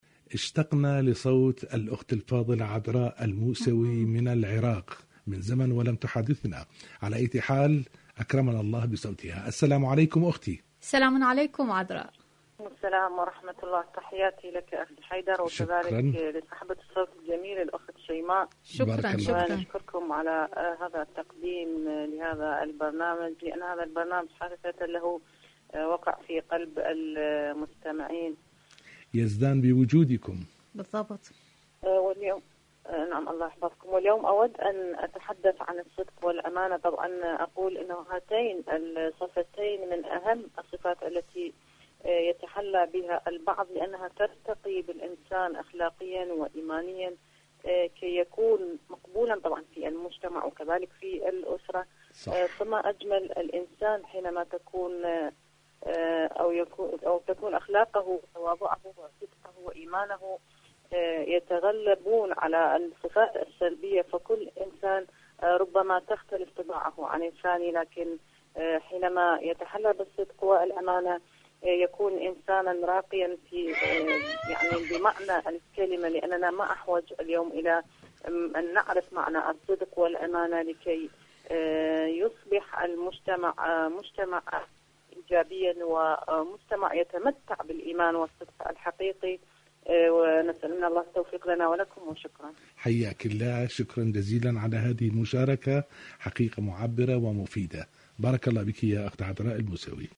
إذاعة طهران-المنتدى الإذاعي